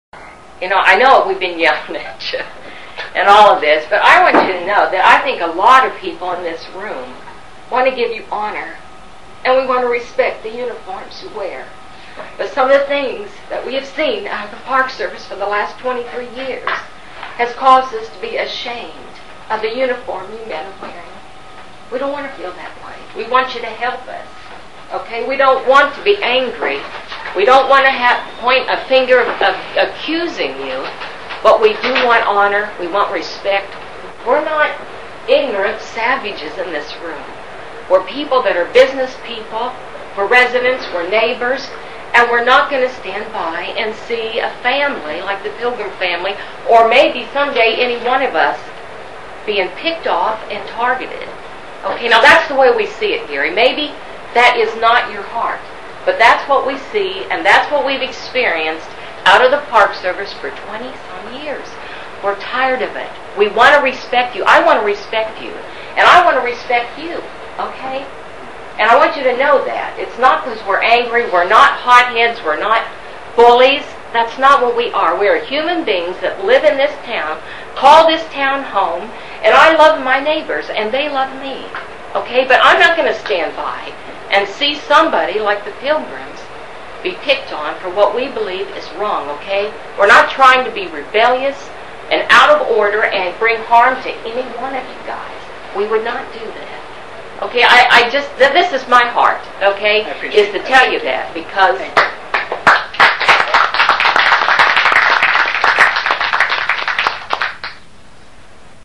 at NPS Meeting in McCarthy on the Pilgrim access 0:1:48.